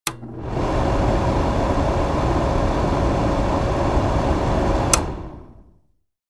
На этой странице собраны звуки кинопроектора в высоком качестве – от мягкого гула до характерных щелчков пленки.
Звук вентилятора проектора охлаждение или неисправность